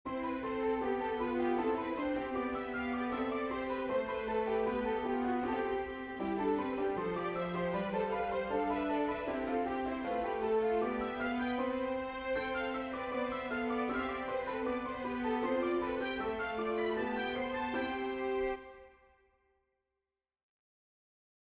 My first attempt at a three voice counterpoint - Piano Music, Solo Keyboard - Young Composers Music Forum
My first attempt at a three voice counterpoint